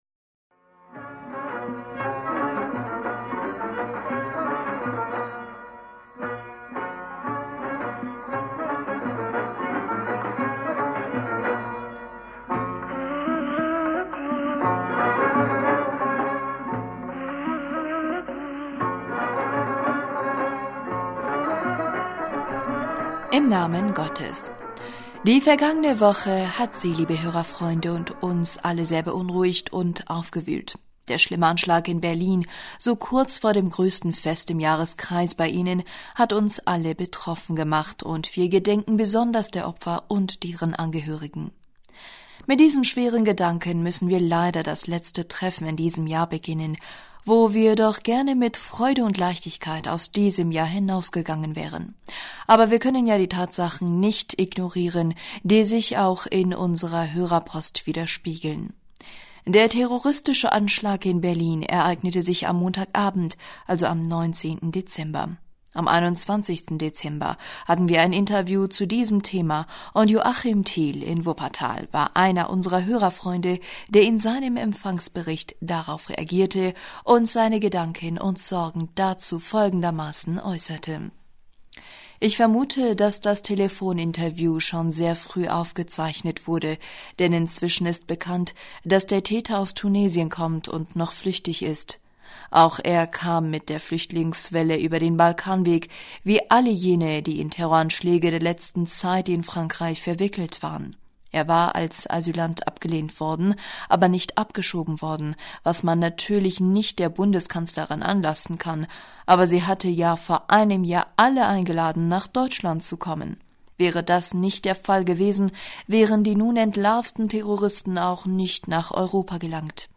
Hörerpostsendung am 25. Dezember 2016 - Bismillaher rahmaner rahim - Die vergangene Woche hat Sie liebe Hörerfreunde und uns alle sehr beunruhigt...